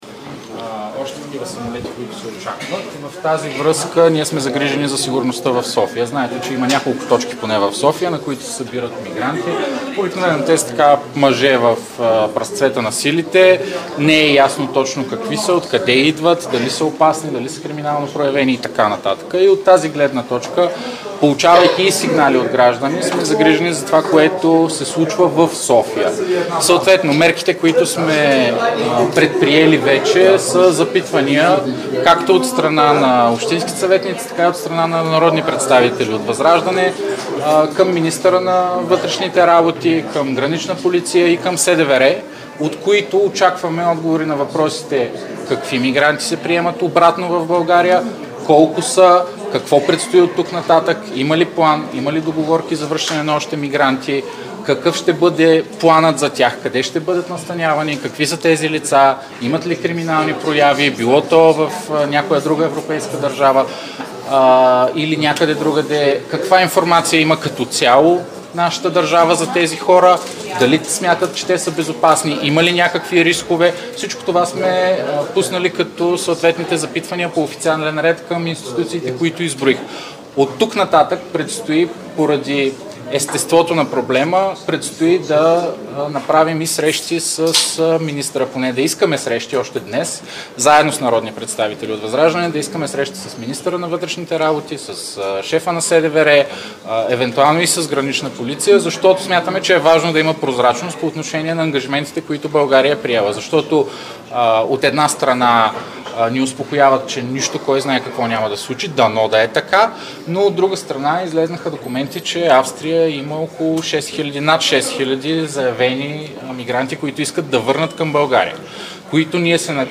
10.10 - Заседание на СОС.
- директно от мястото на събитието („Московска" 33)